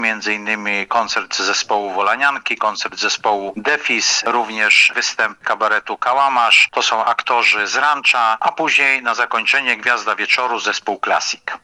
Będzie także wiele atrakcji, mówi burmistrz Jedlni-Letniska Piotr Leśnowolski: